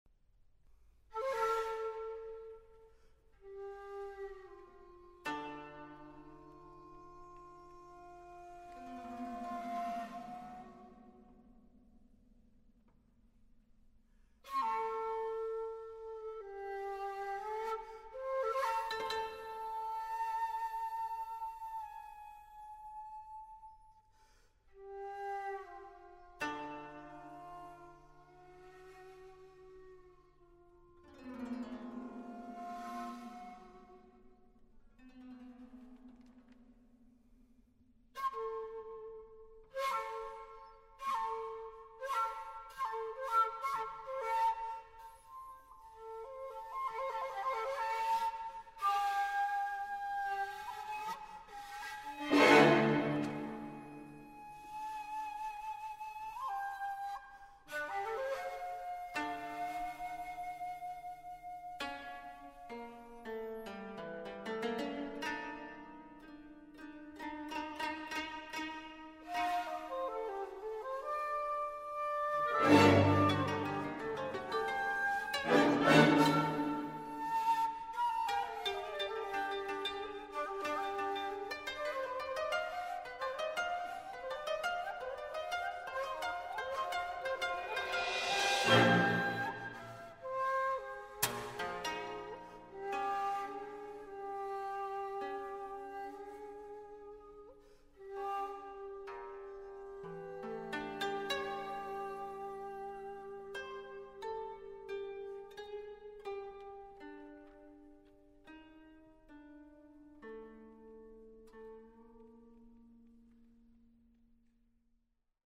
shakuhachi, 21-string koto & orchestra